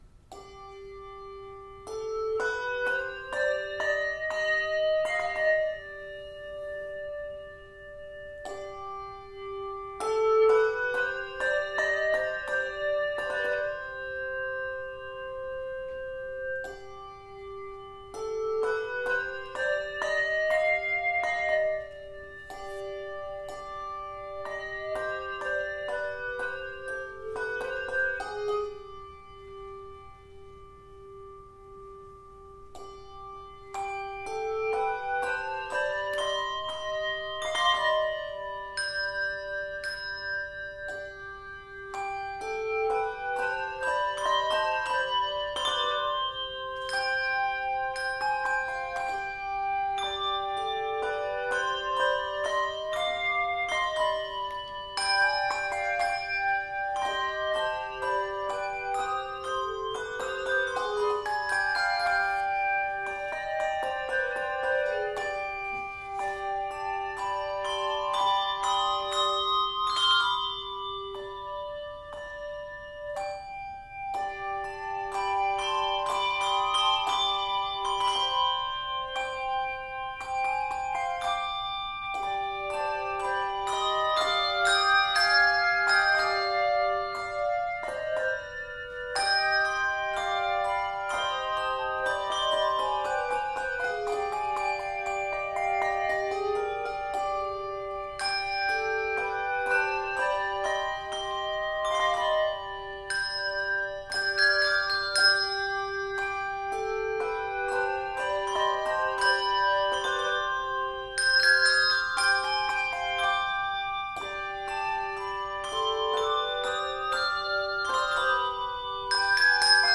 Stunning and transparent
Octaves: 2-3